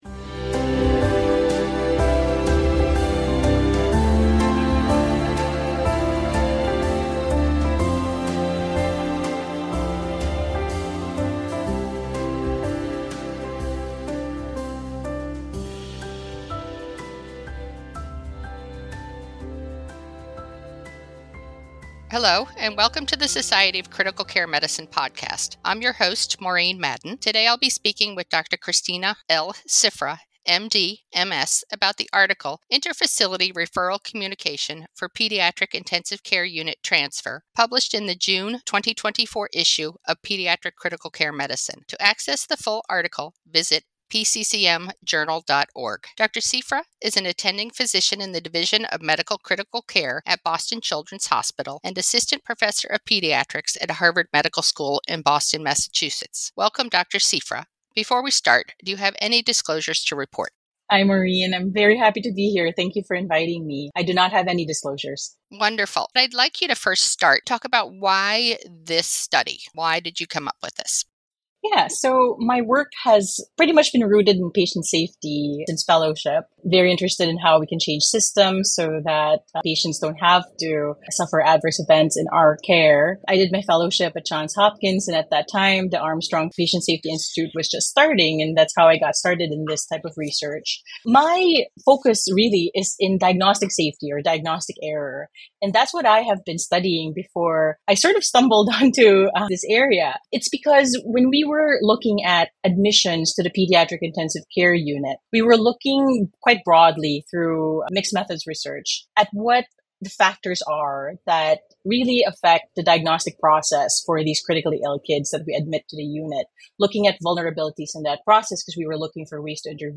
The Society of Critical Care Medicine (SCCM) Podcast features in-depth interviews with leaders in critical care. Experts discuss hot topics in intensive care with perspectives from all members of the critical care team.